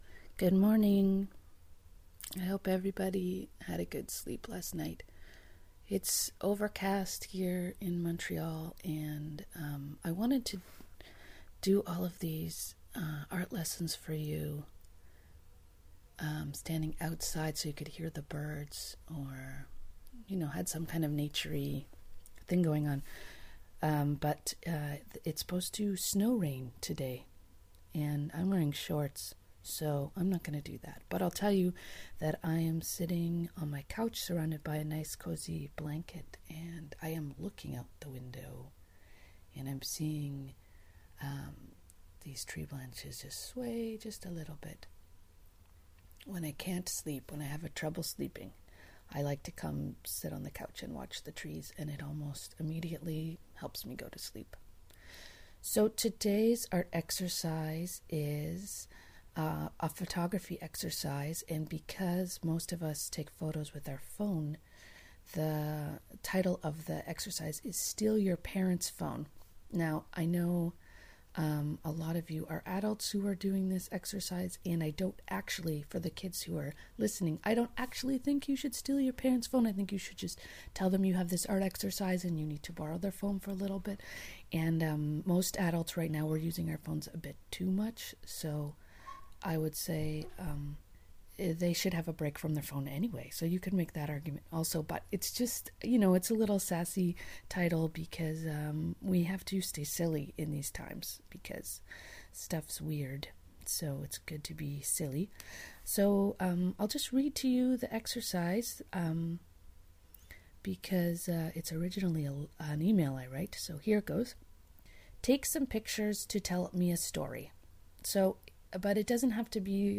160kbps Stereo